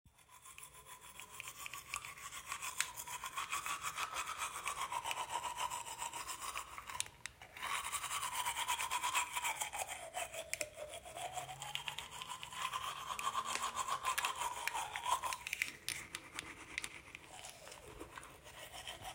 Brushing Teeth